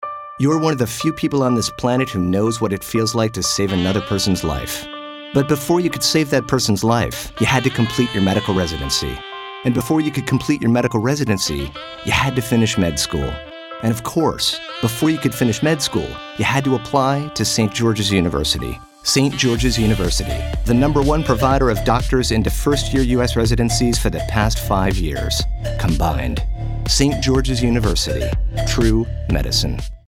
Saint George's University "Real Medicine" I was given the opportunity from Michael Walters Advertising to find the audio track for this spot. This gave me more of a chance to strengthen my skill for placing the correct audio track to voice overs and style of commercial.